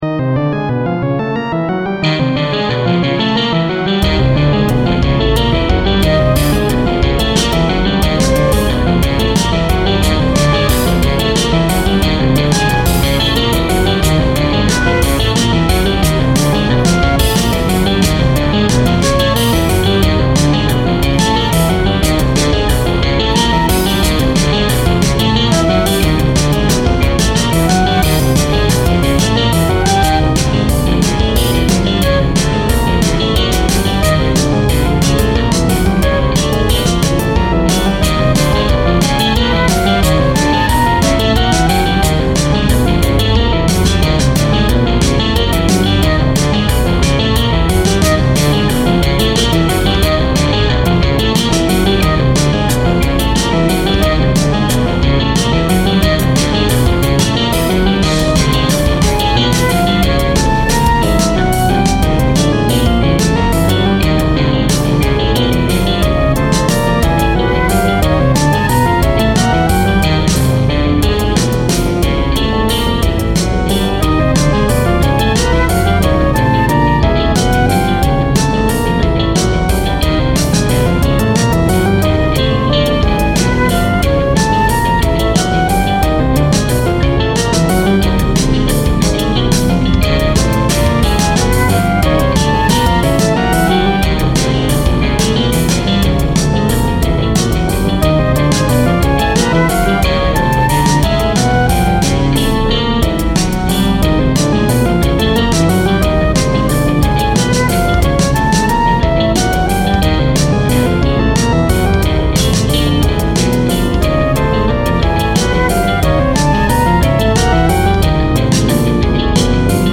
Guitar
Kbd, Dr
Tuning: 12edo